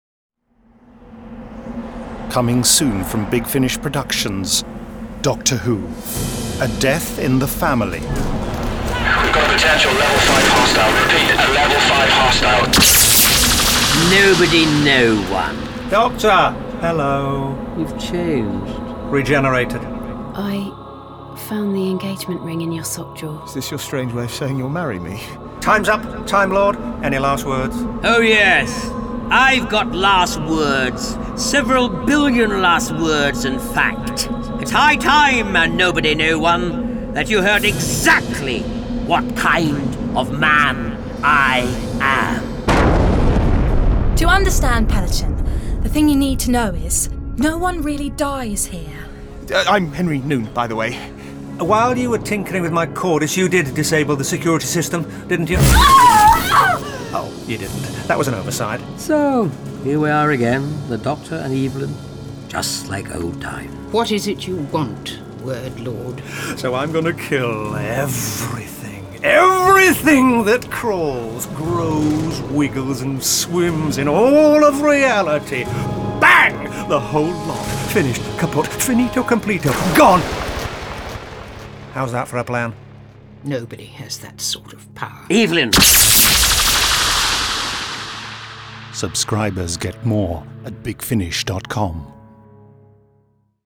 Award-winning, full-cast original audio dramas
Starring Sylvester McCoy Sophie Aldred